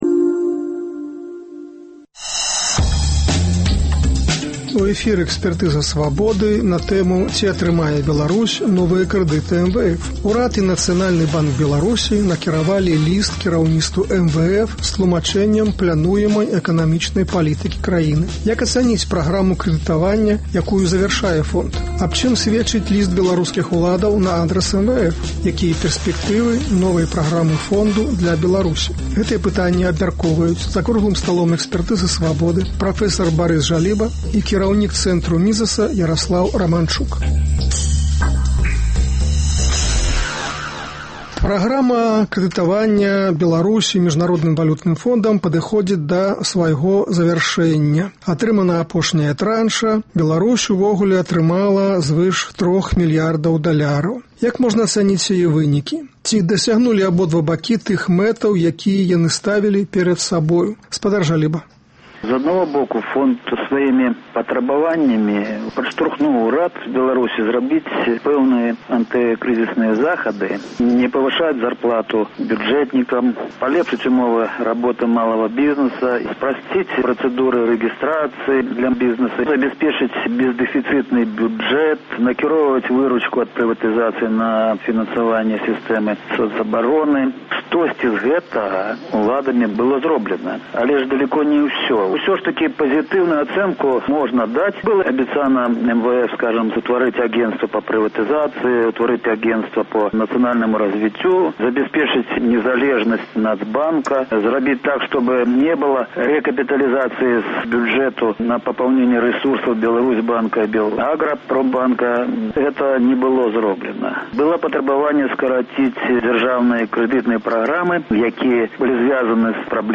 Як ацаніць праграму крэдытаваньню, якую завяршае фонд? Аб чым сьведчыць ліст беларускіх уладаў на адрас МВФ? Якія пэрспэктывы новай праграмы фонду для Беларусі? Гэтыя пытаньні абмяркоўваюць за круглым сталом